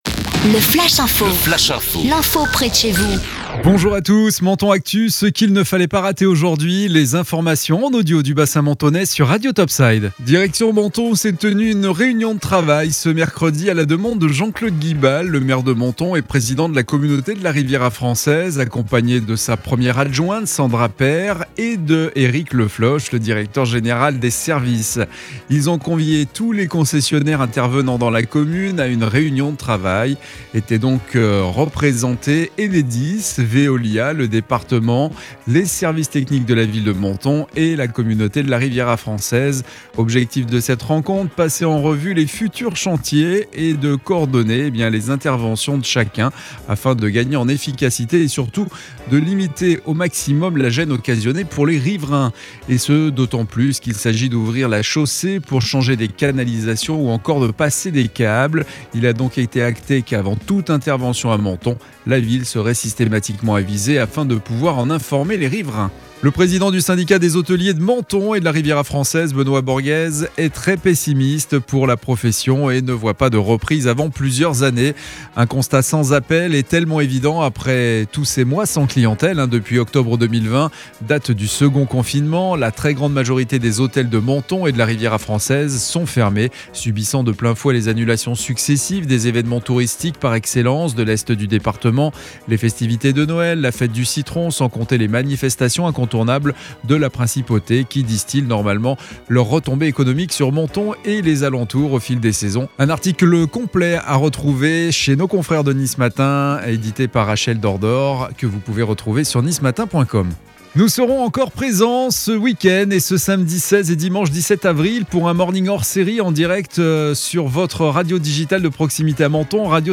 Menton Actu - Le flash info du jeudi 15 avril 2021